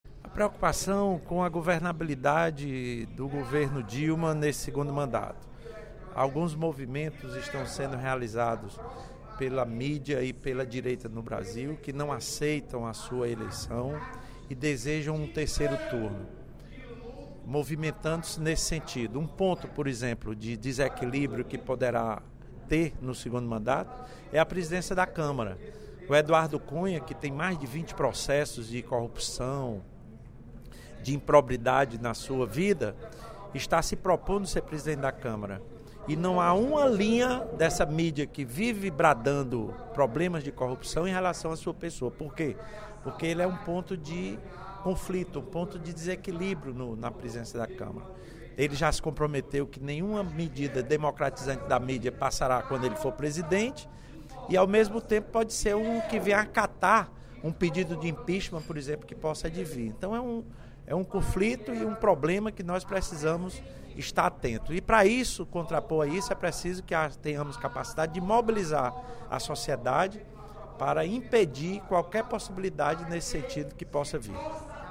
O deputado Lula Morais (PCdoB) comparou, em pronunciamento no primeiro expediente da sessão plenária desta terça-feira (11/11), a resistência de setores da imprensa à eleição de Dilma Rousseff ao que ocorreu na campanha desencadeada por Carlos Lacerda, da década de 1950, contra Getúlio Vargas.